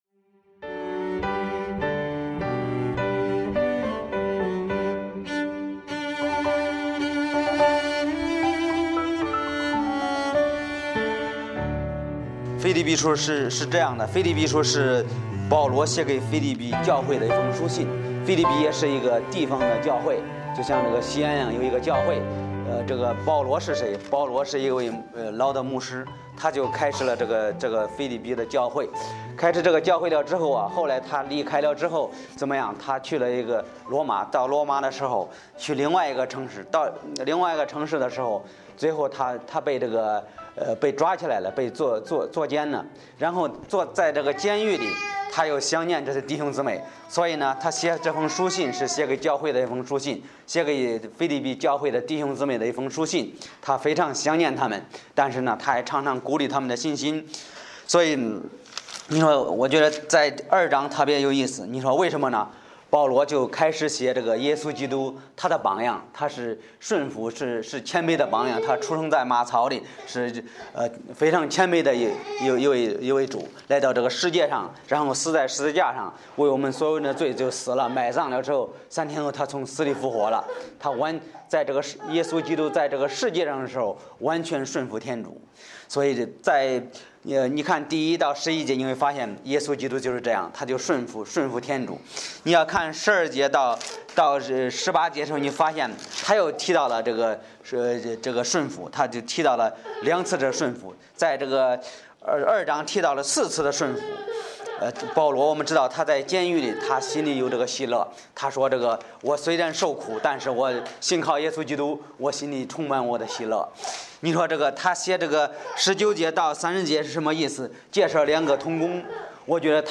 Bible Text: 腓立比书2:19-30 | 讲道者